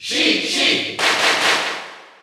Category: Crowd cheers (SSBU) You cannot overwrite this file.
Sheik_Cheer_Dutch_SSBU.ogg.mp3